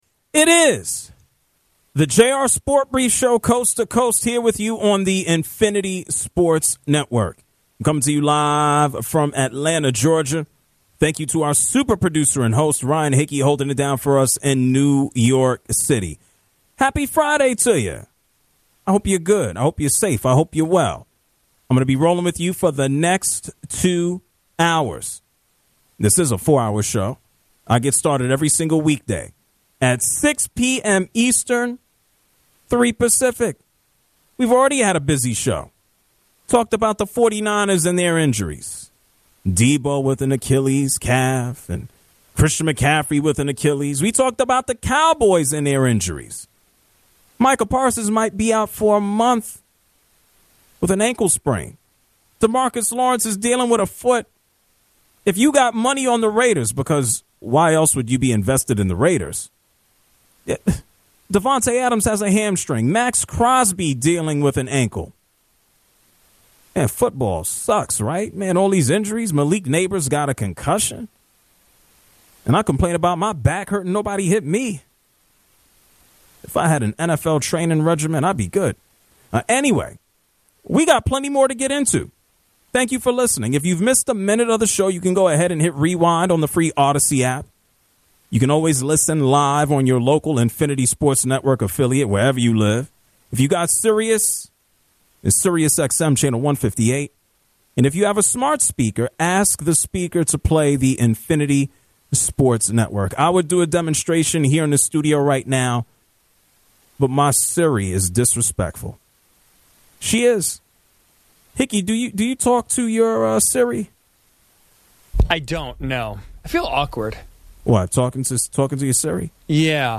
The hour concludes by taking calls on the Bills-Ravens game.